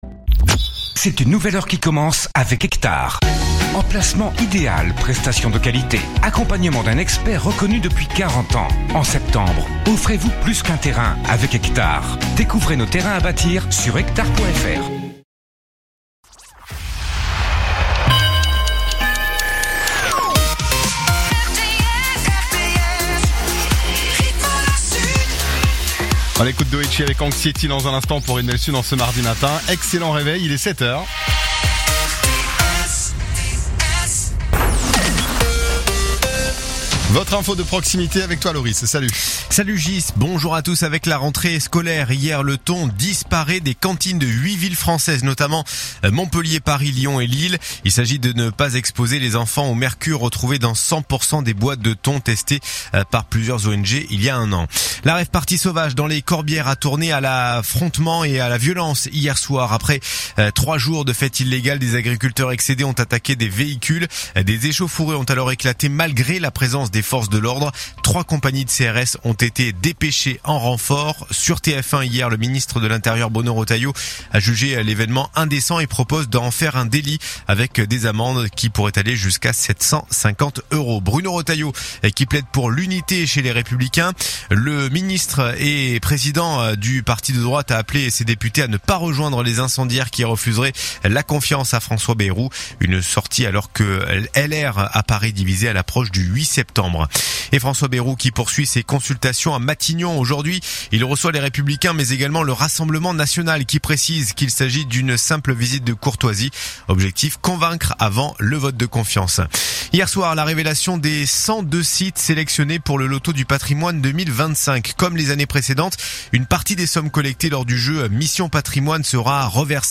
Écoutez les dernières actus de l'Hérault en 3 min : faits divers, économie, politique, sport, météo. 7h,7h30,8h,8h30,9h,17h,18h,19h.